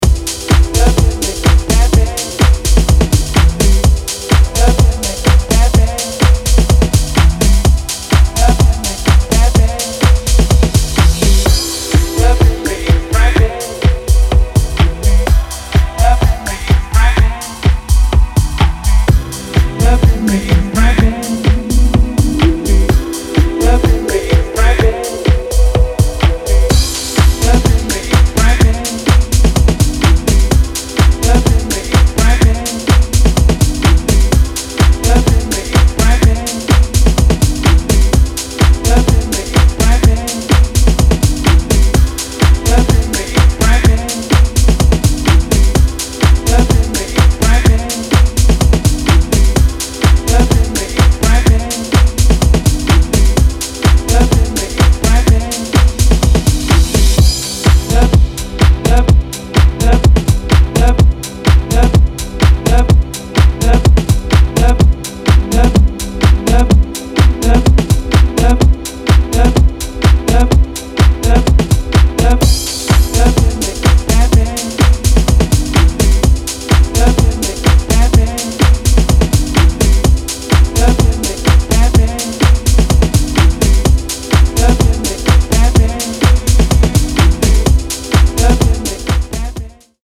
ジャジーコードとヴォーカルが反復するビートダウン・ハウス